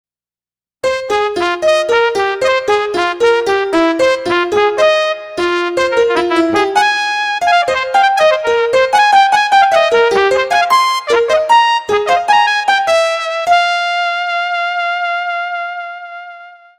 イントロの部分も練習してみましたが駄目でした。倍テンポでもこのざまでしたorz.